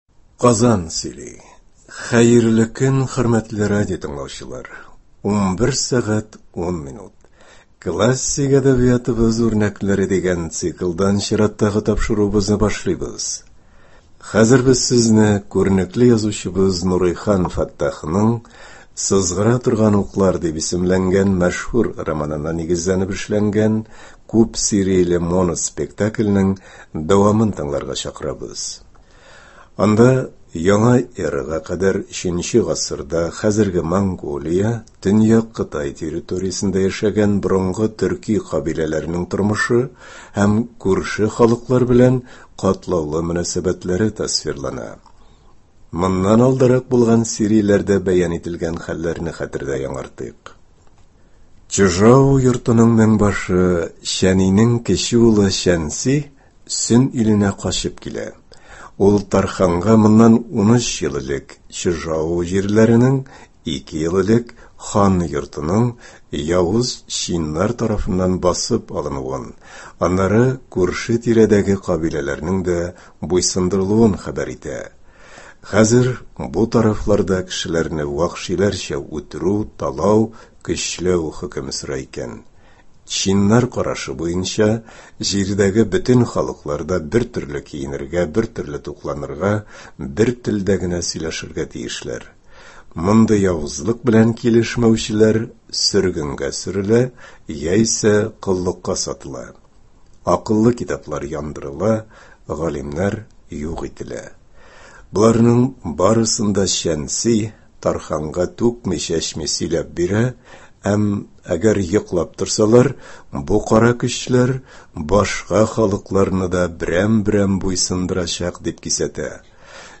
“Сызгыра торган уклар”. Моноспектакль.
Моноспектакль.